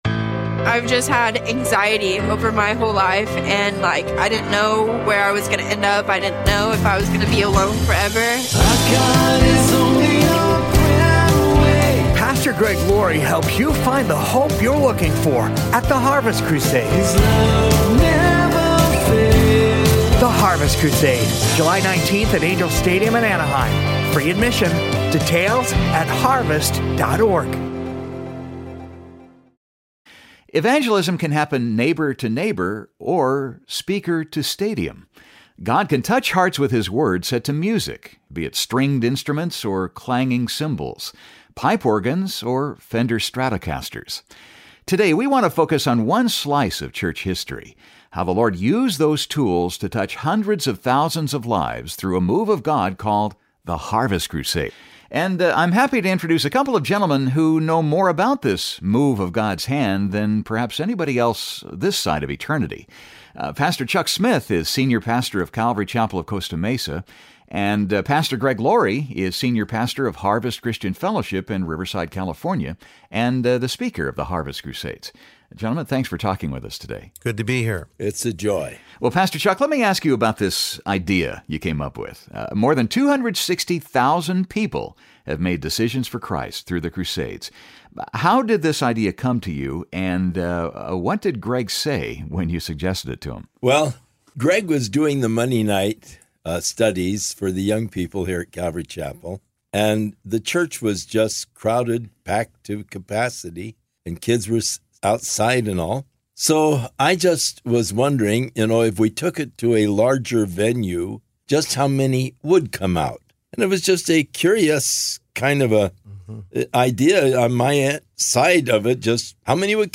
Revisit this timeless interview as Pastor Greg Laurie sits down with his mentor, the late Pastor Chuck Smith, to share the origins of the Harvest Crusade and captivating behind the scenes stories.